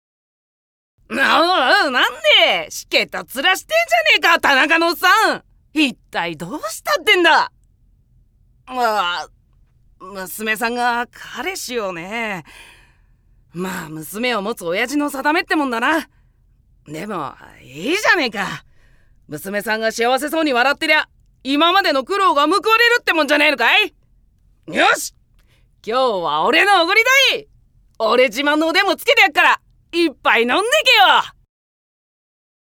◆おでん屋の少年◆